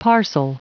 Prononciation du mot parcel en anglais (fichier audio)
Prononciation du mot : parcel